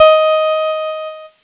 Cheese Note 09-D#3.wav